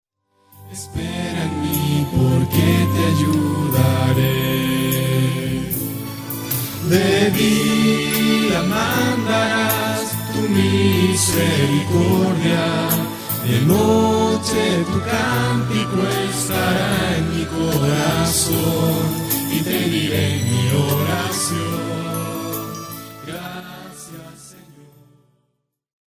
llena de adoración y reverencia